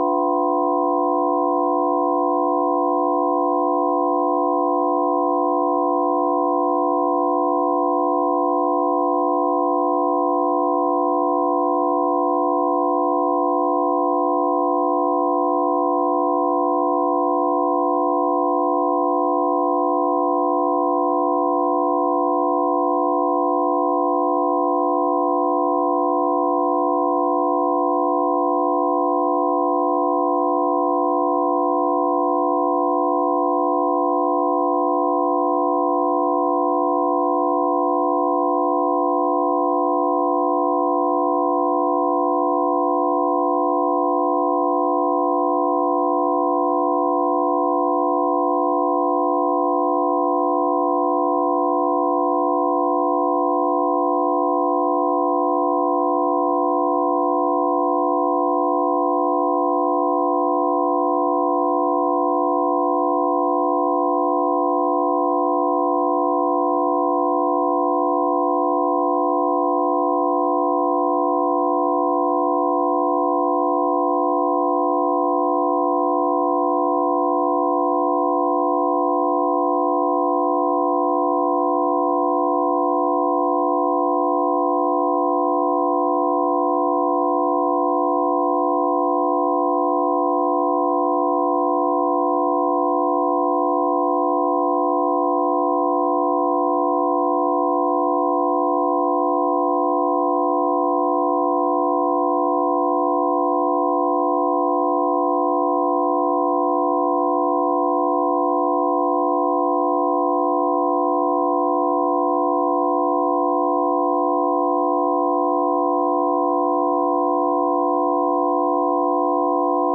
基于我发现的研究 我做了一些超声处理（将数据缩放到可听范围）以“可视化”听起来如何。 注意：这是测量数据的超声处理，而不是实际的顺势疗法补救措施。
声道立体声